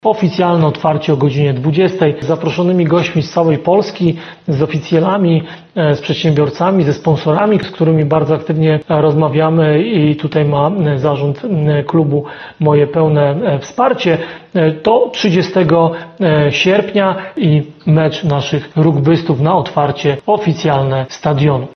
– Oficjalne otwarcie planujemy na godzinę 20.00, z zaproszonymi gośćmi z całej Polski – z oficjelami, przedsiębiorcami, sponsorami Sandecji Nowy Sącz. Będzie też mecz naszych rugbystów – mówi Ludomir Handzel, prezydent Nowego Sącza.